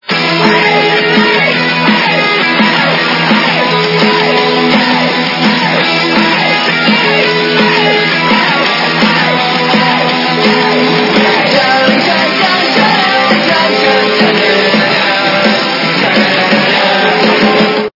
- западная эстрада
При заказе вы получаете реалтон без искажений.